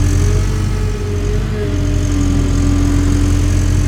CONSTRUCTION_Digger_Digging_03_loop_mono.wav